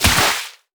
water_spell_impact_hit_06.wav